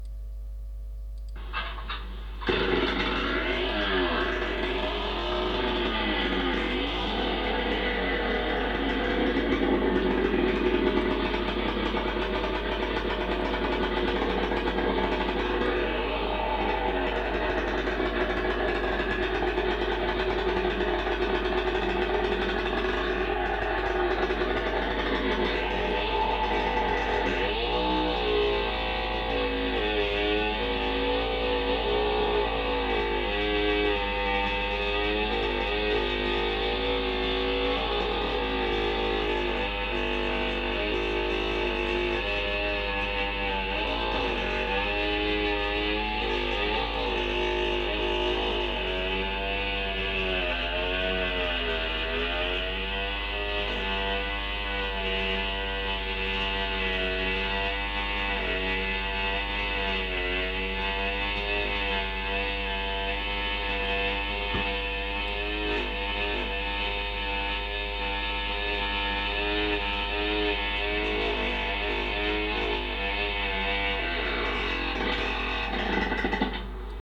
Tronçonneuse - Sons d'une tronçonneuse 1 39799
• Catégorie: Scie à chaîne